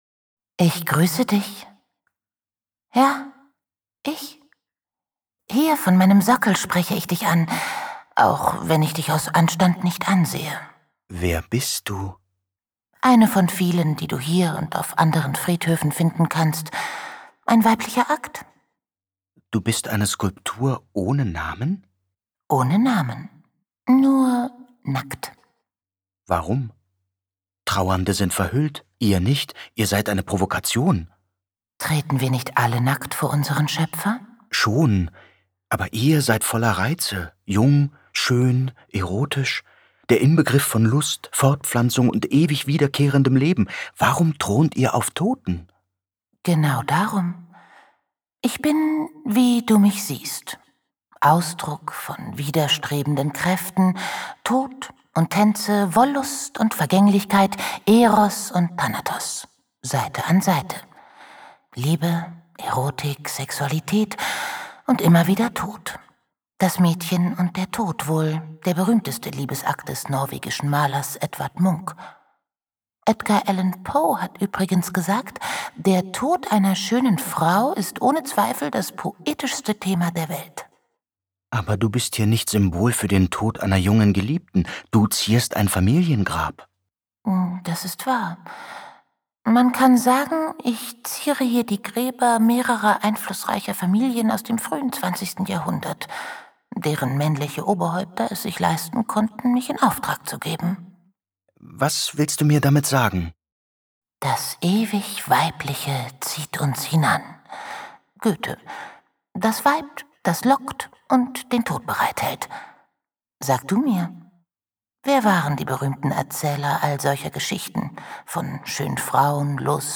Ein Hörspaziergang
Sprechende Skulpturen
Begehbarer Hörspiel-Parcour